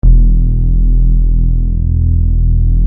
Underground Orgasmic Bass K 2000 E1 org_bass